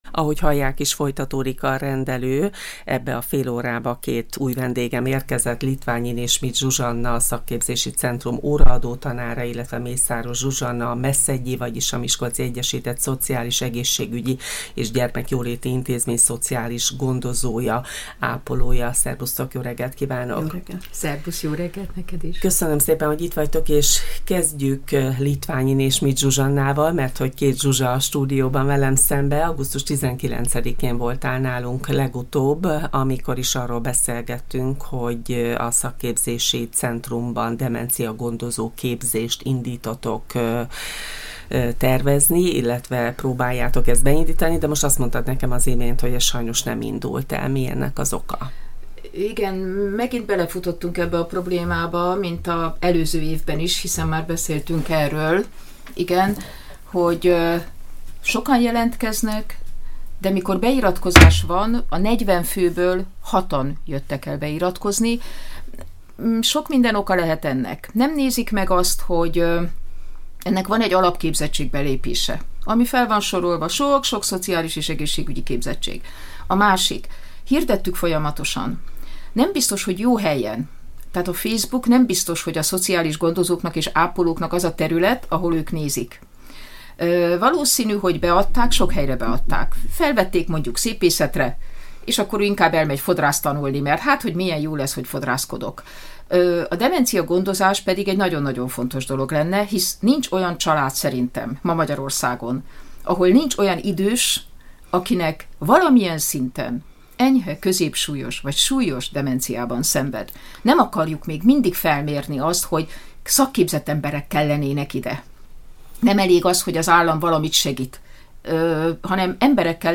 érkezett a stúdióba, akikkel a demenciáról és a demencia gondozás aktuális kérdéseiről beszélgettünk. A szakemberek megfogalmazták: nagy szükség és igény lenne ún. demencia napközik létrehozására, ahol az érintettek, nappali ellátásban részesülnének.